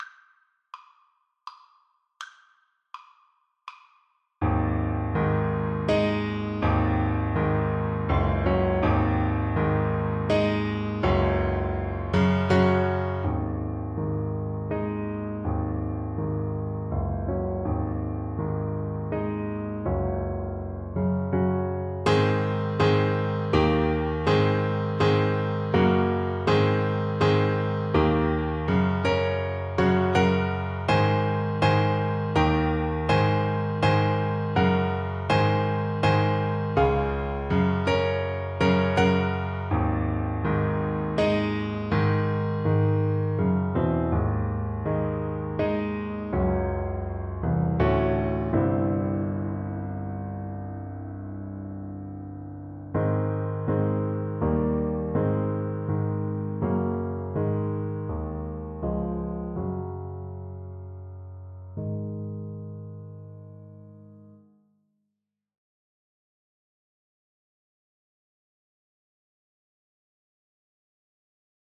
Alto Saxophone
Traditional Music of unknown author.
9/8 (View more 9/8 Music)
Ab4-Eb6
Presto . = c.120 (View more music marked Presto)
Slip Jigs for Alto Saxophone
Irish